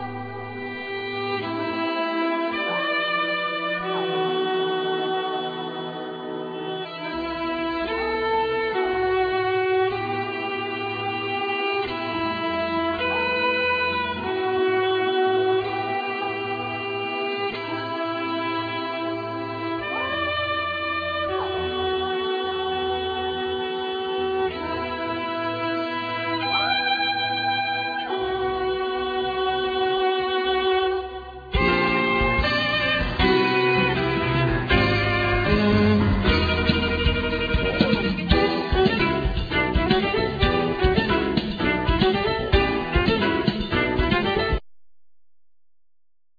Violin
Piano,Keyboards
Bass
Drums,Percussions